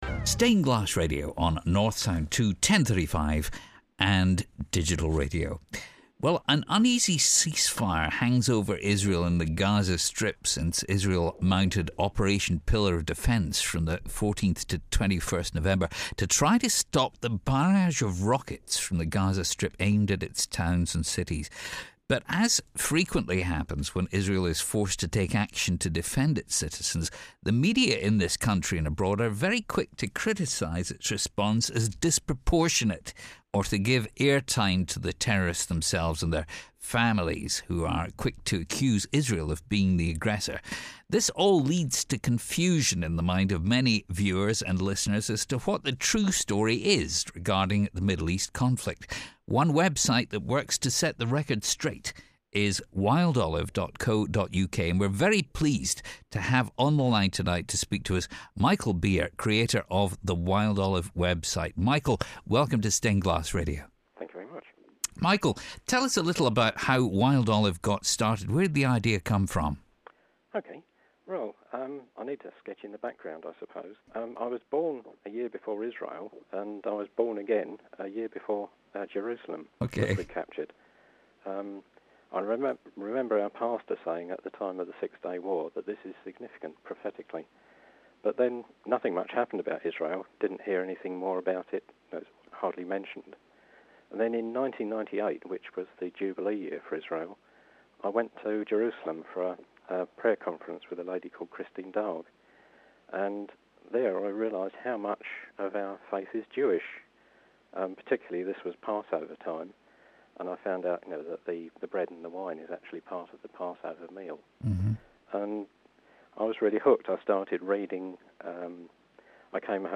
wildolive interview.mp3